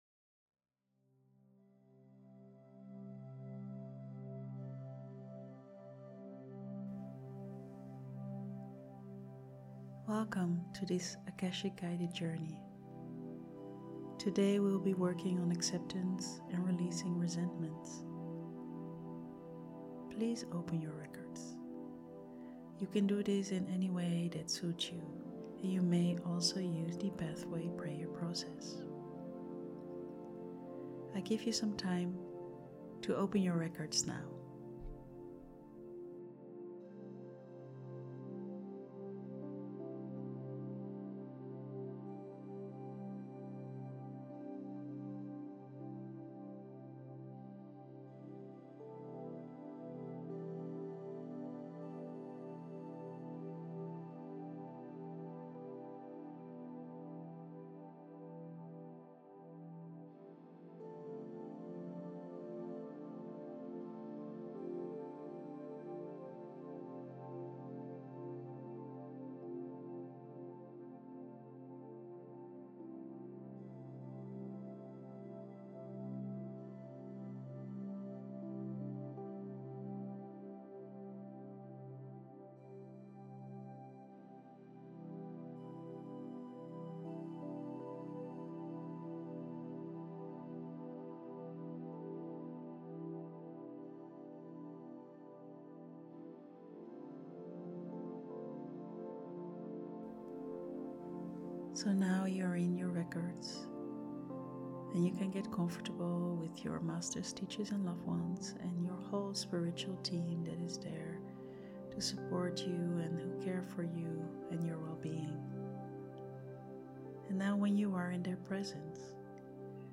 Akasha Guided Journey